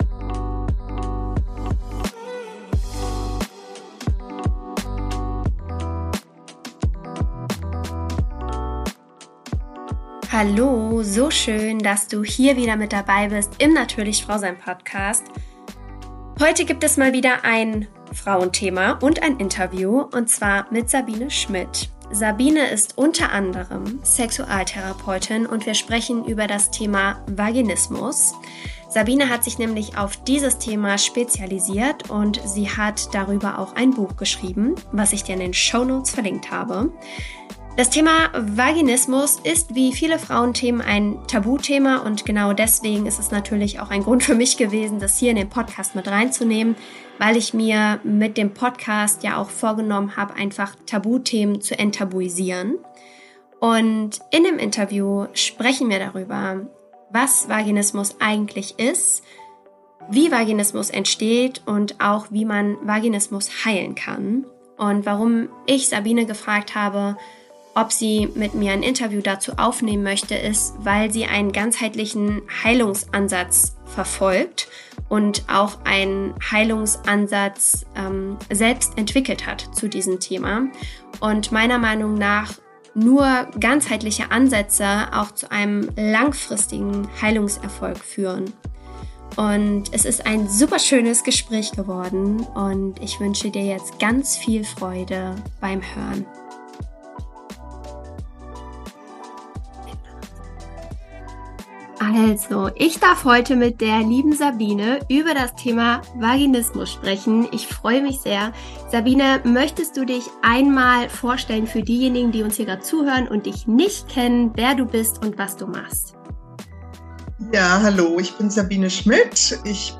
In dem heutigen Interview geht es um das Thema Vaginismus.